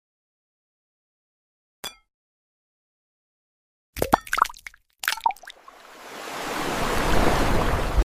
Cracking an Egg of Ocean sound effects free download